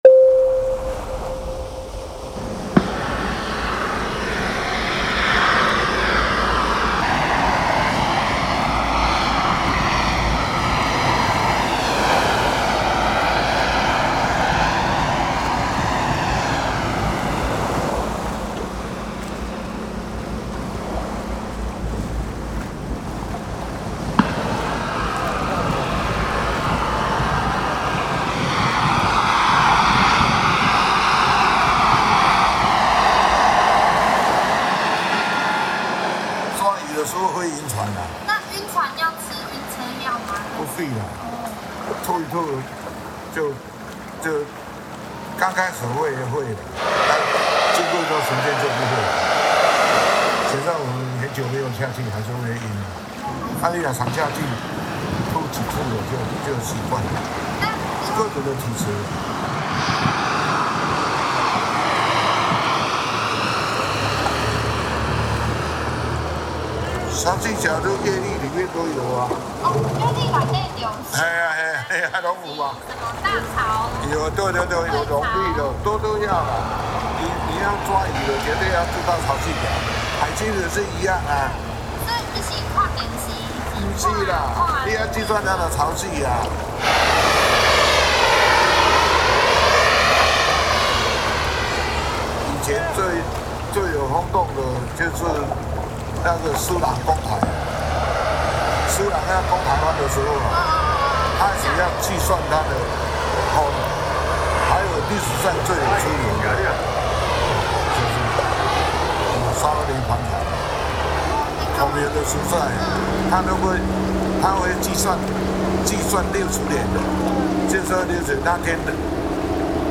These tracks feature environmental recordings, oral histories, and storytelling, echoing the voices, rhythms, and transformations of coastal life in Jinshan.
濤聲蹦火 Booming Tides, Flickering Fires: An aural journey through the traditional sulfuric fire fishing (蹦火仔 bèng-huó-á) off the coast of Jinshan, capturing the explosive sounds of acetylene flames, crashing waves, and stories shared by veteran fishermen.